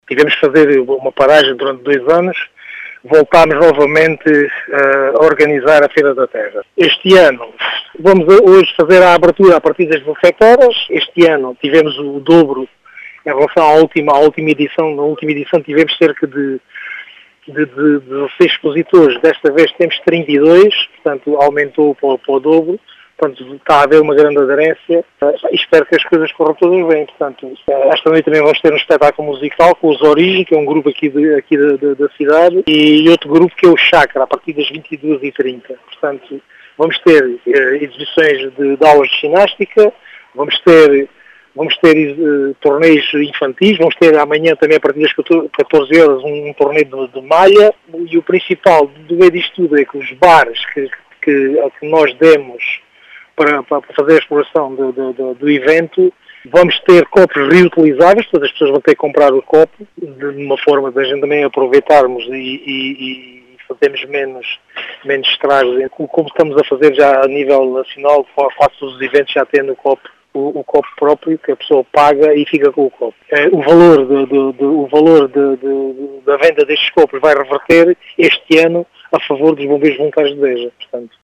As explicações são de António Ramos,  presidente da União de Freguesias de Salvador e Santa Maria da Feira, que explica que o evento teve uma “grande aderência, ao nível dos expositores.